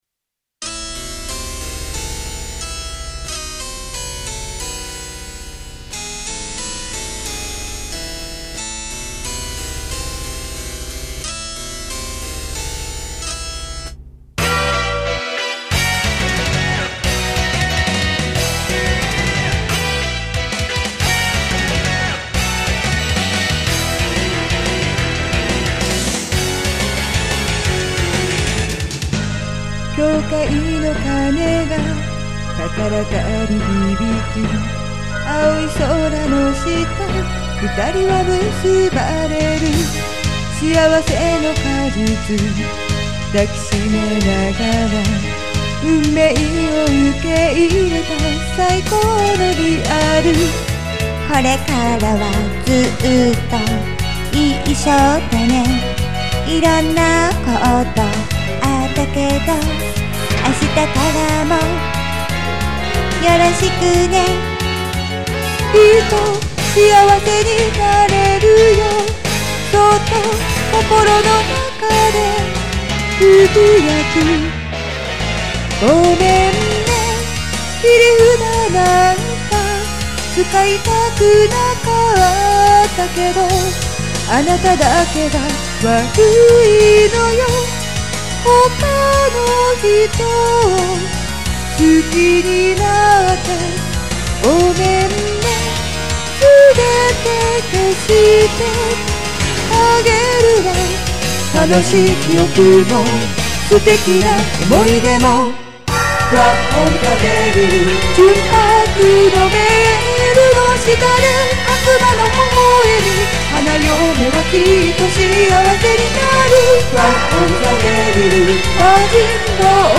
歌の最後に笑い声なんぞ入れております。